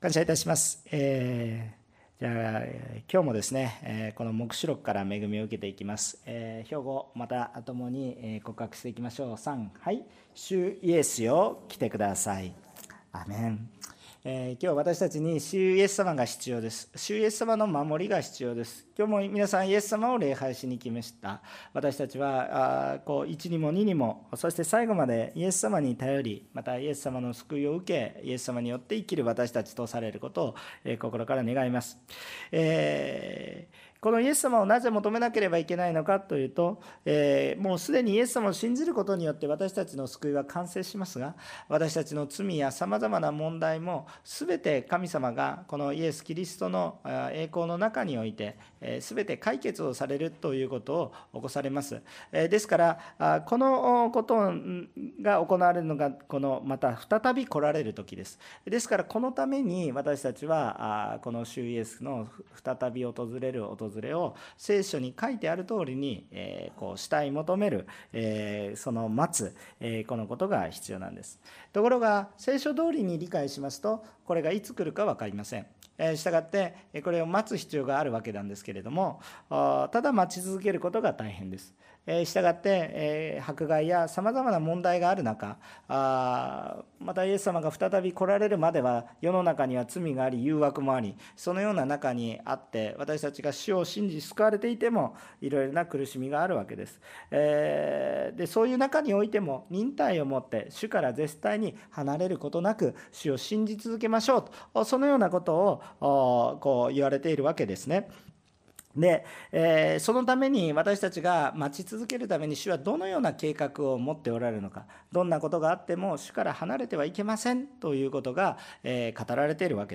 横浜オンヌリキリスト教会の説教を配信します。